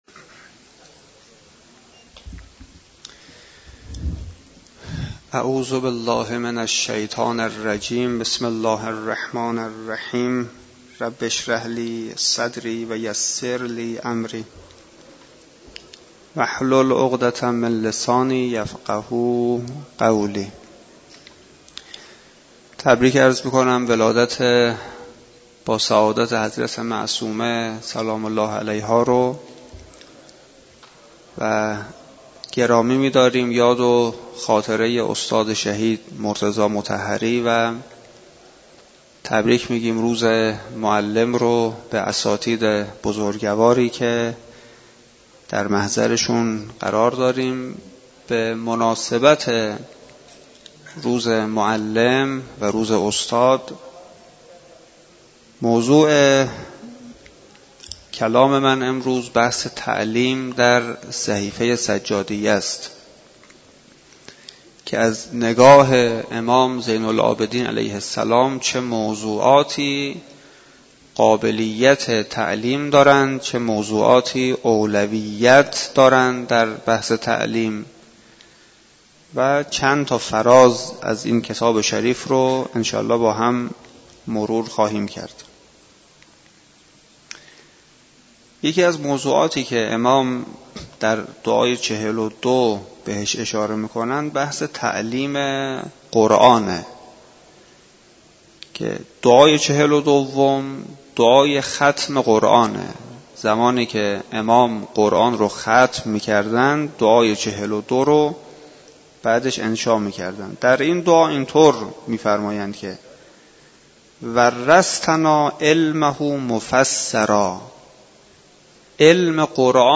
در مسجد دانشگاه کاشان برگزار گردید.